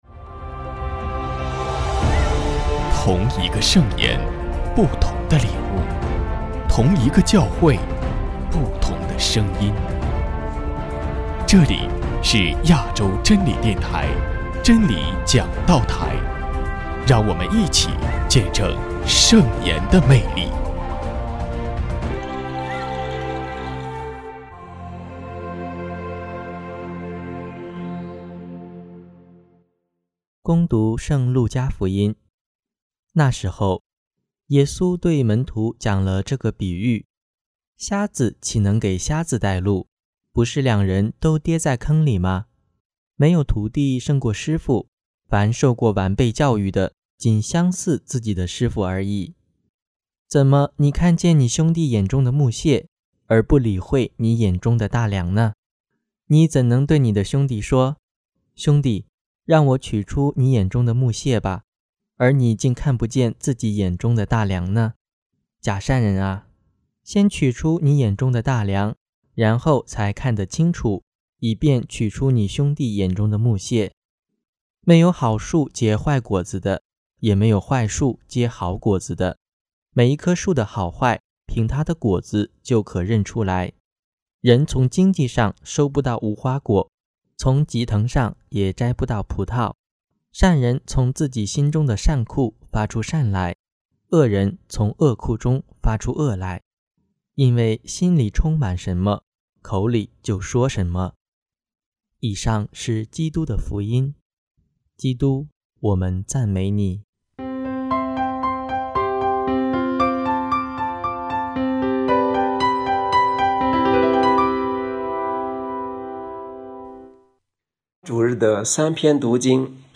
【真理讲道台 】61|常年期第八主日证道